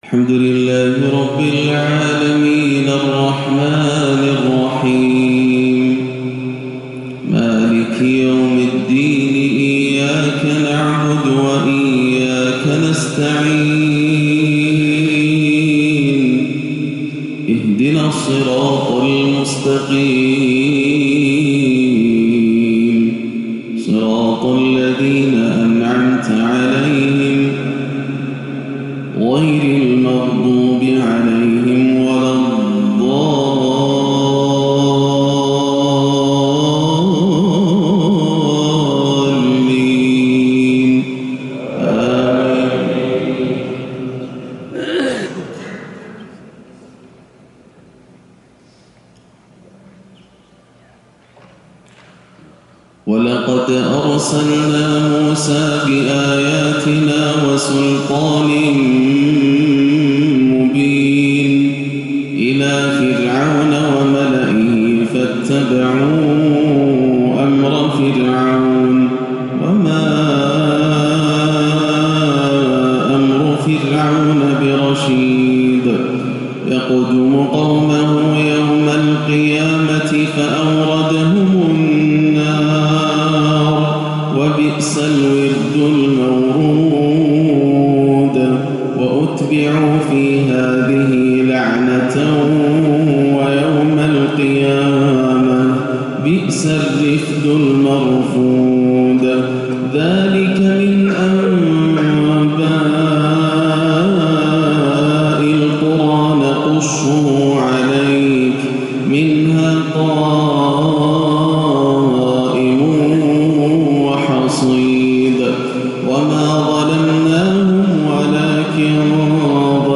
فجر الإثنين 9-3-1439هـ من سورة هود 96-115 > عام 1439 > الفروض - تلاوات ياسر الدوسري